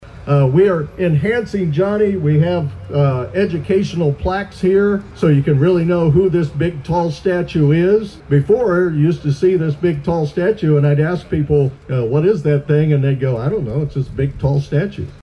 Former mayor Ed Klimek also spoke to the crowd about the refurbished Johnny Kaw statue